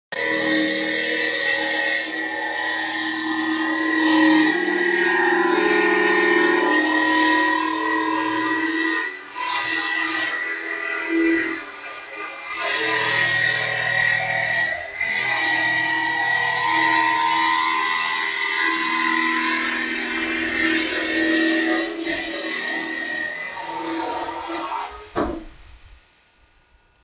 Сверление или дробление
• Категория: Ремонтные работы
• Качество: Высокое